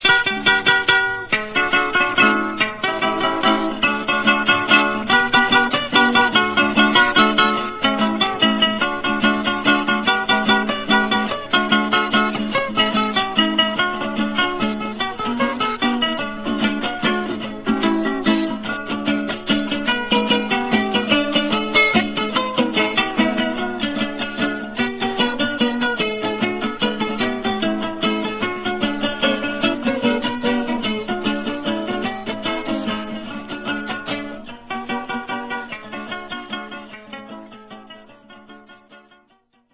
MEJORANA
Laúd guitarra.
Las cinco cuerdas de nylon están sostenidas directamente al portacuerdas, sin puente. Se ejecuta rasgueada, para acompañar puntos, torrentes, tonos o toques en las fiestas de baile o cantadera, según la región.
Característica: Torrente, propio de las fiestas de baile o cantaderas
mejorana.ra